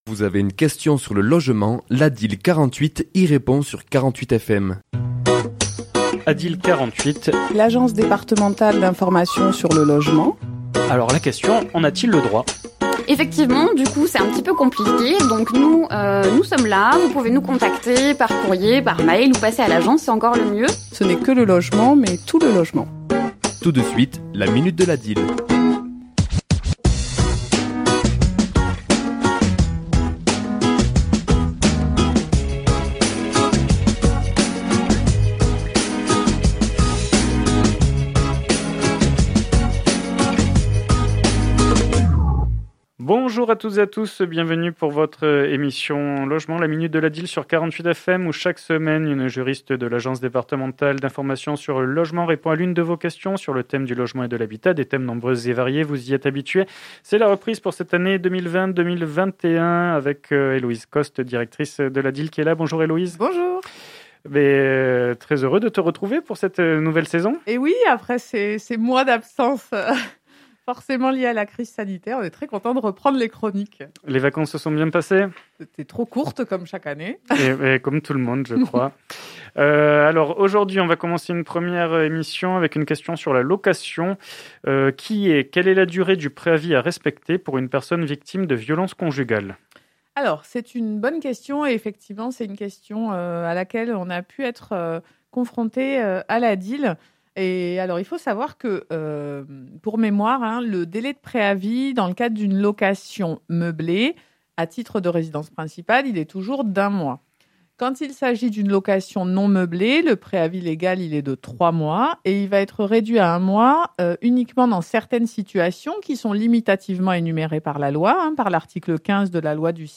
Chronique diffusée le mardi 8 septembre et  le jeudi 10 septembre à 11h00 et 17h10